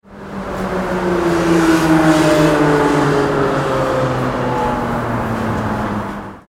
Realistic Large Motorbike Passing Busy City Street Sound Effect
A large motorbike accelerates through busy city streets. Its engine roars as it passes cars and pedestrians.
Realistic-large-motorbike-passing-busy-city-street-sound-effect.mp3